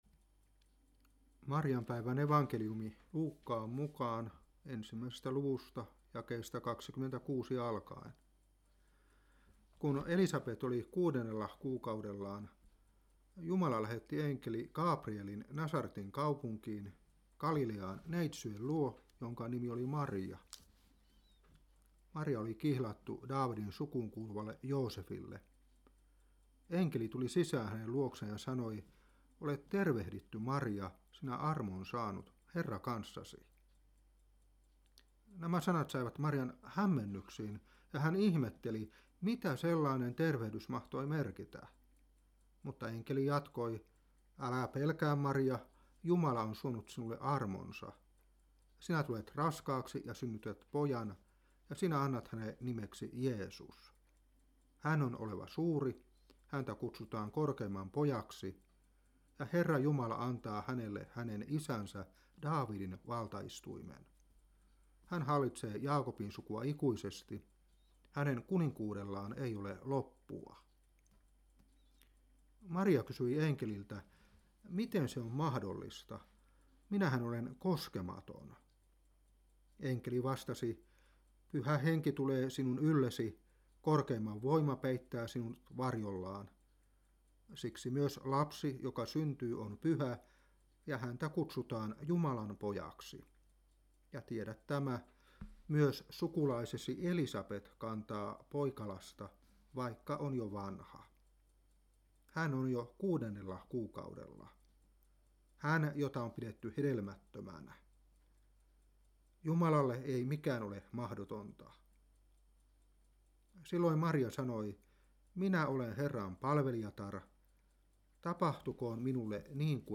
Seurapuhe 2013-6.